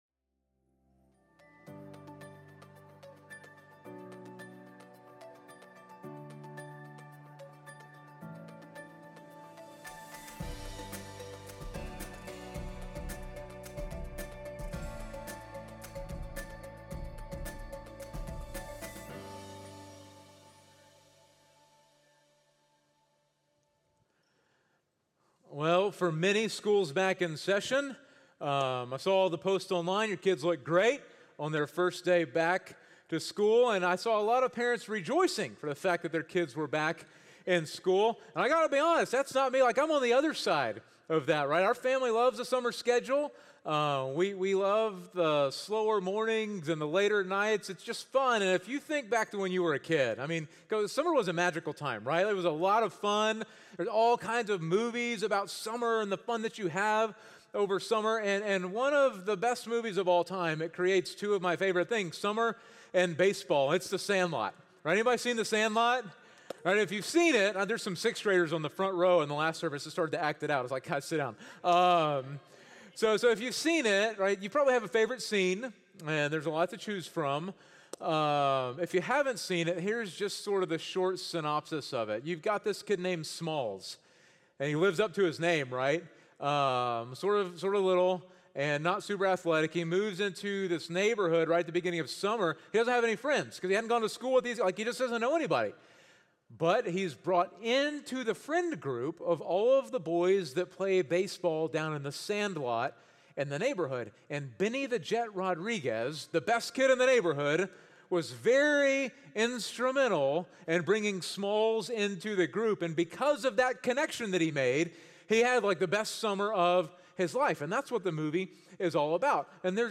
A message from the series "The Thread - JV."